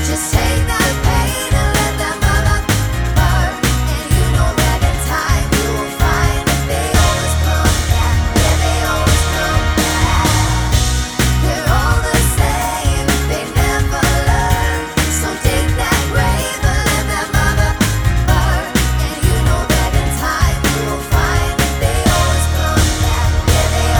clean Pop (2010s) 3:22 Buy £1.50